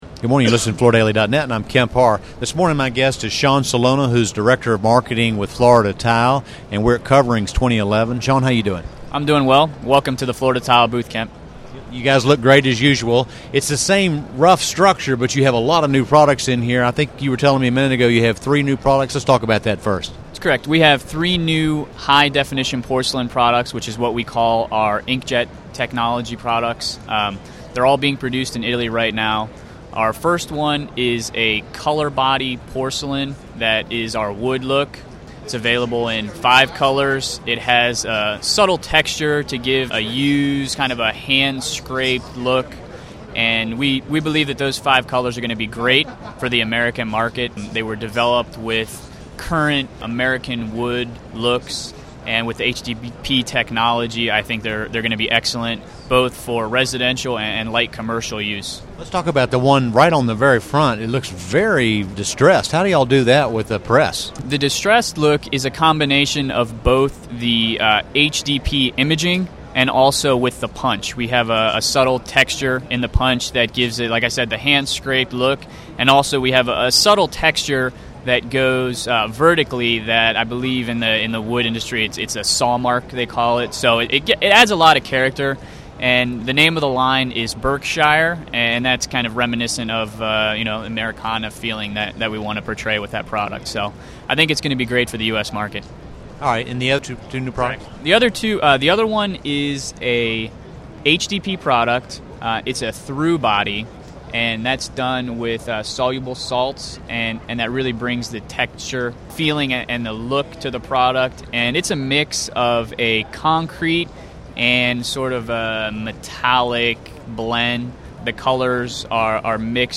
Listen to the interview to hear more about how they achieve these new visuals and also learn more about Florida Tile's new programs for 2011.